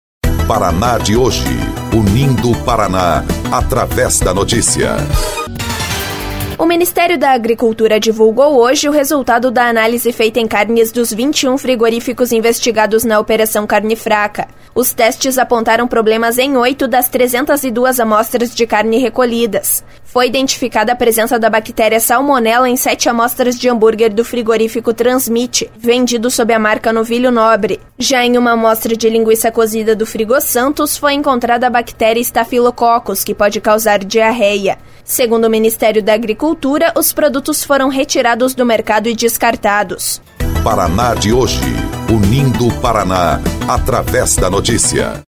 BOLETIM – Teste encontra bactérias em 8 de 302 amostras de carne de frigoríficos